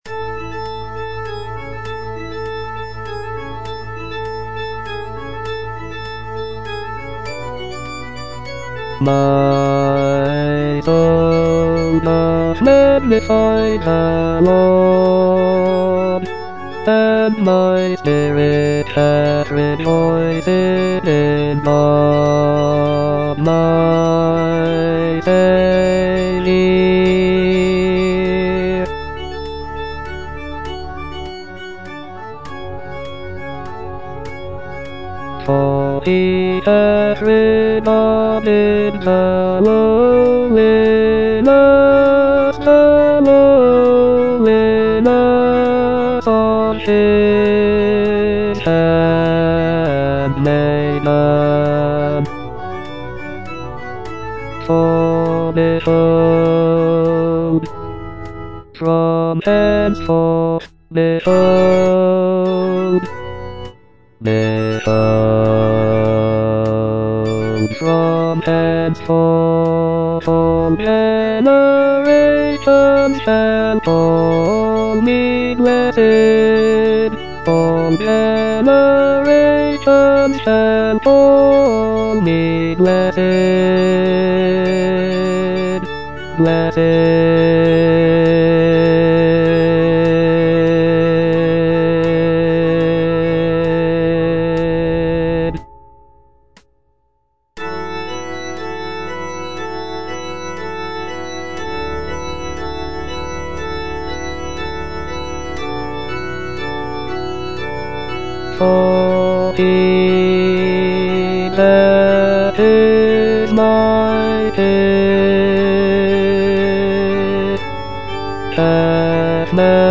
Bass (Voice with metronome) Ads stop